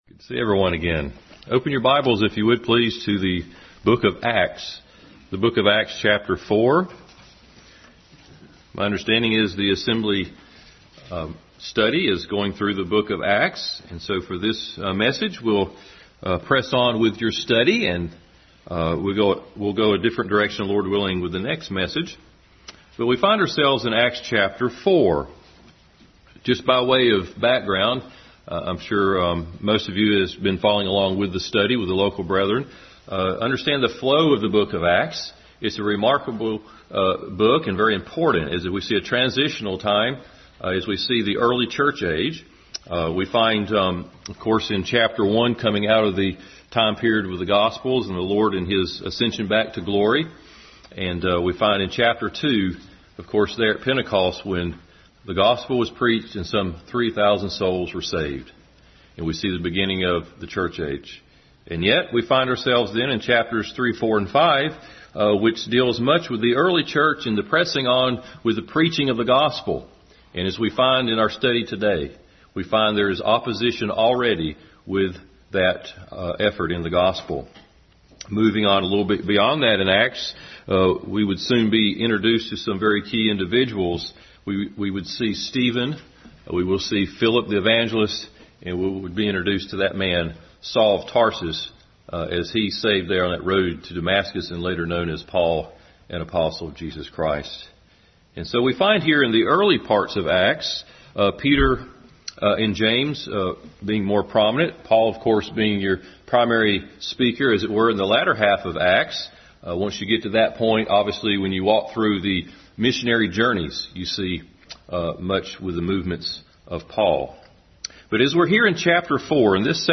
Acts 4 Service Type: Sunday School Bible Text